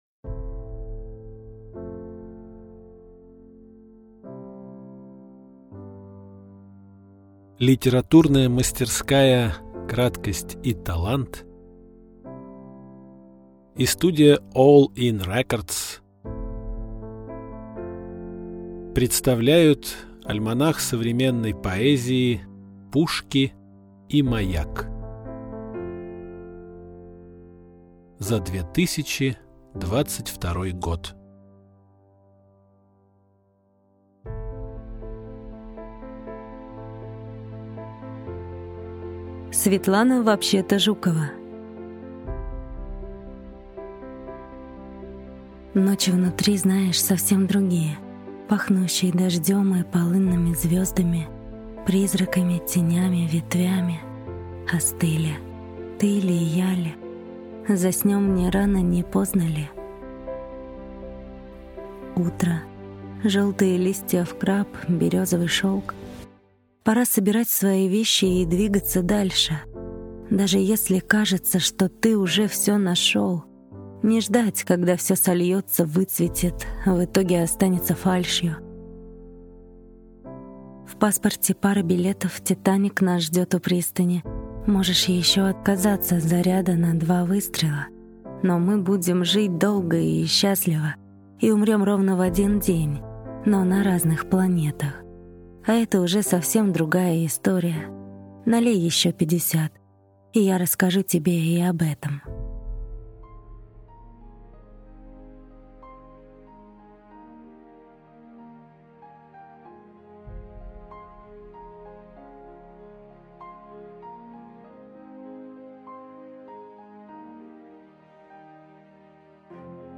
Альманах-2022 Автор Сборник Читает аудиокнигу Актерский коллектив.